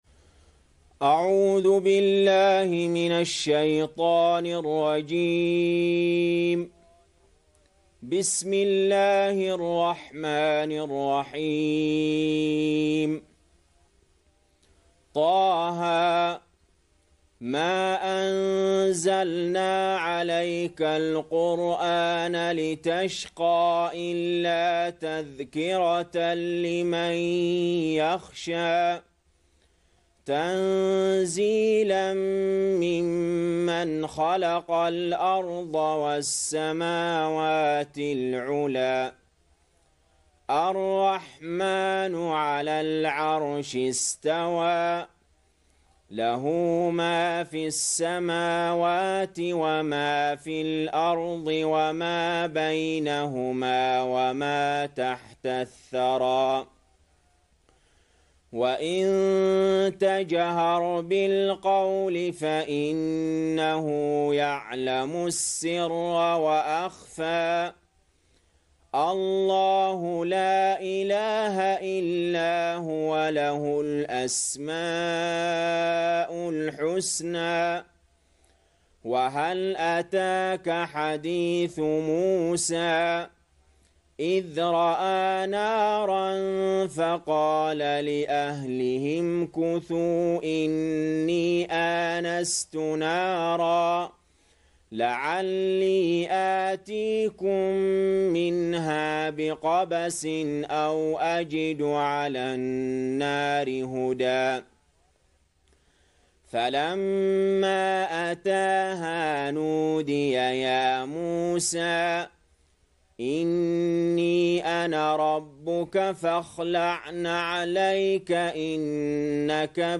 | ماتيسر من سورة طه 1-36 | > تلاوات الشيخ علي جابر في التلفزيون السعودي عام 1408هـ > تلاوات الشيخ علي جابر خارج الحرم > المزيد - تلاوات الحرمين